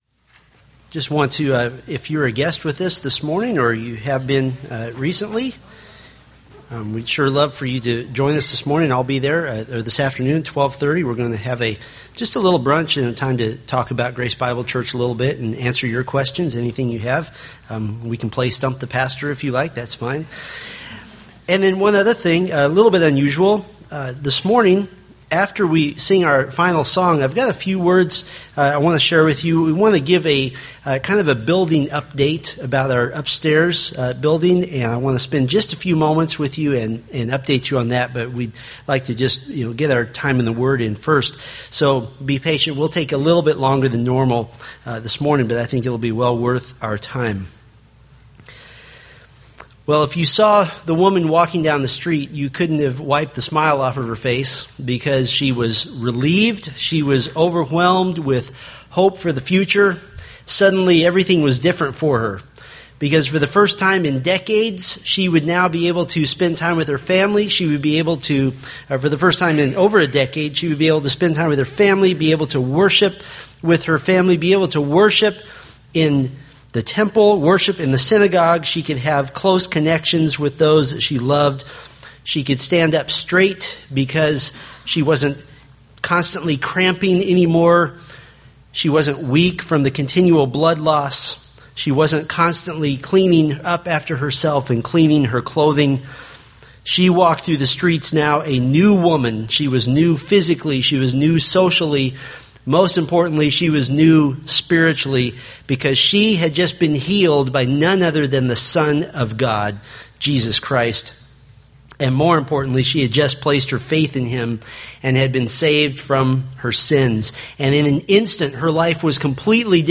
Mark Sermon Series